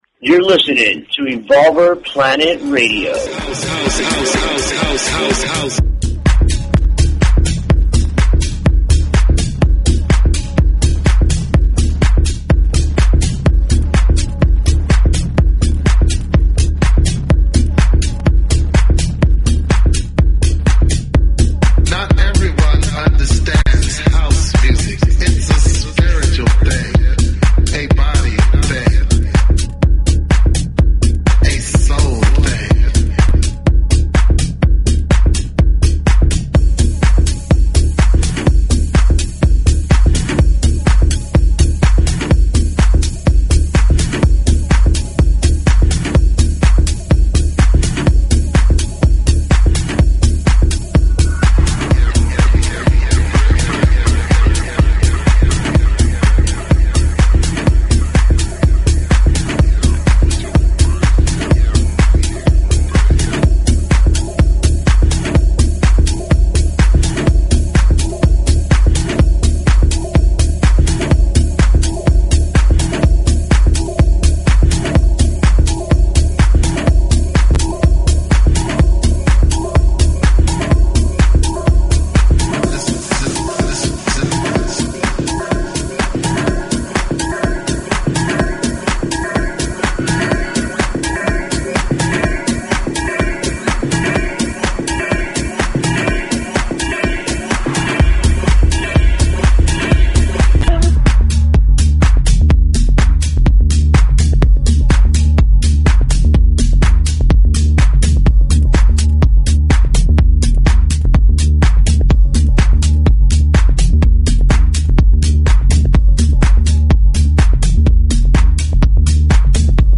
Talk Show Episode, Audio Podcast, Evolver_Planet_Radio and Courtesy of BBS Radio on , show guests , about , categorized as